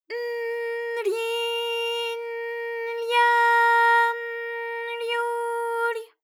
ALYS-DB-001-JPN - First Japanese UTAU vocal library of ALYS.
ry_n_ryi_n_rya_n_ryu_ry.wav